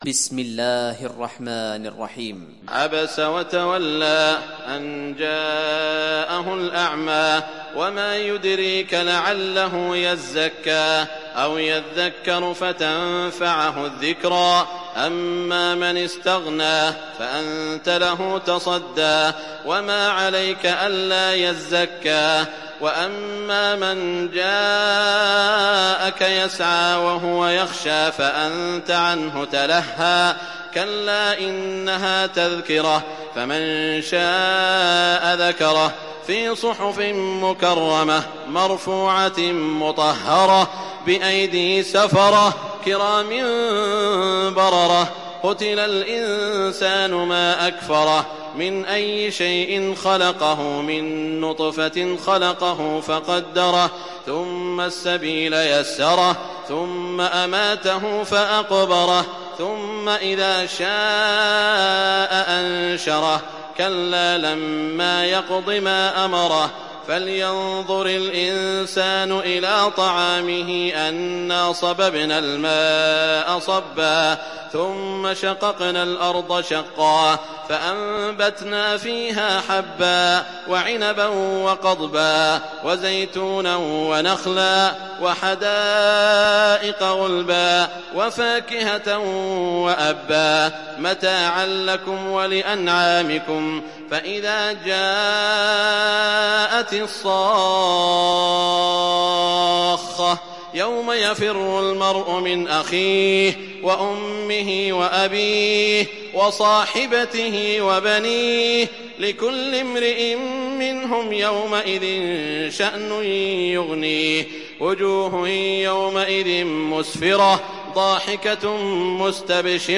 دانلود سوره عبس mp3 سعود الشريم روایت حفص از عاصم, قرآن را دانلود کنید و گوش کن mp3 ، لینک مستقیم کامل